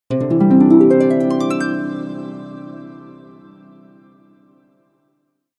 Notification Sounds / Sound Effects
Success-harp-sound-effect.mp3